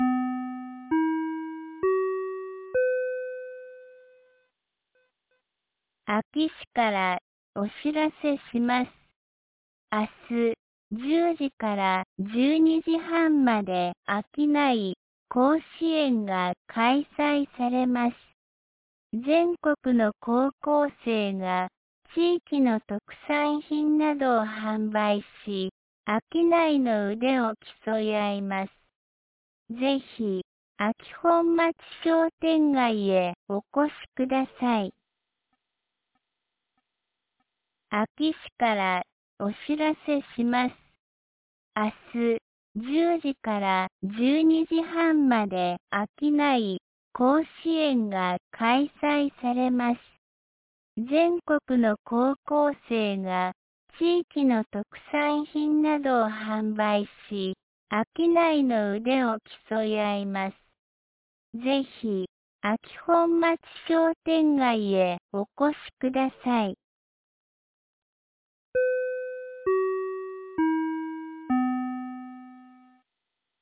2025年10月25日 17時16分に、安芸市より全地区へ放送がありました。